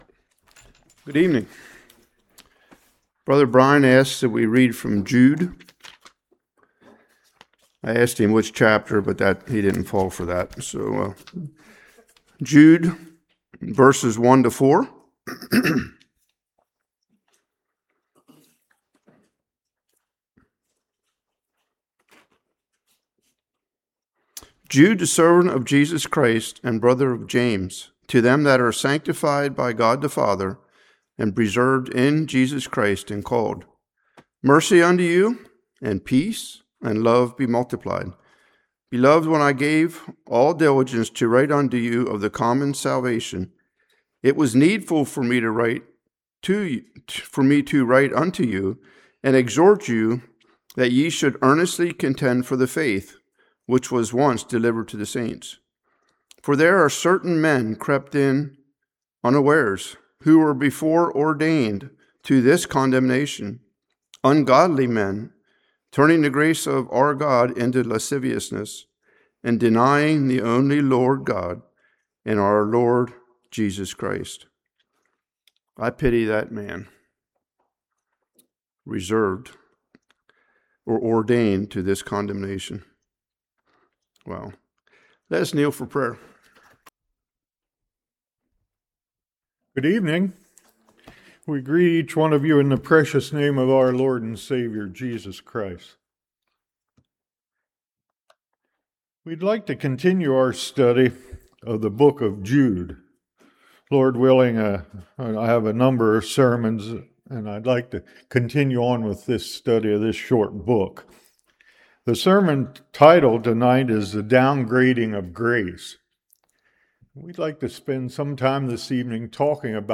Jude 1-4 Service Type: Evening False Teaching of Grace.